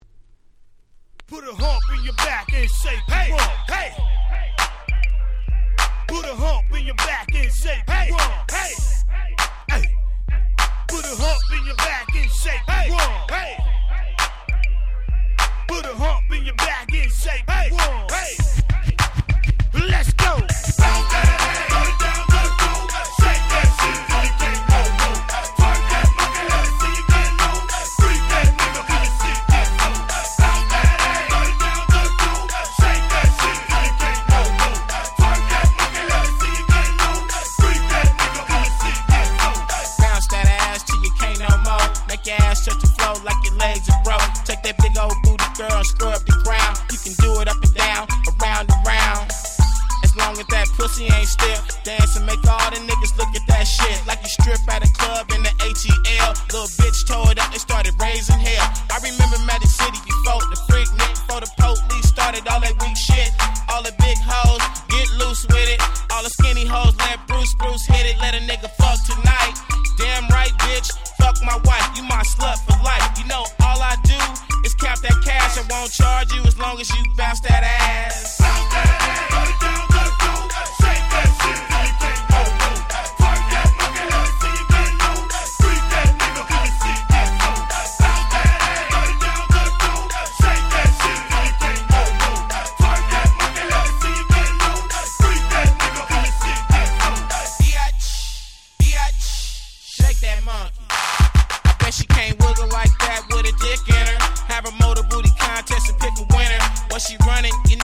Southern Hip Hop Classics !!
福生や六本木ではお約束のお尻フリフリチューン！！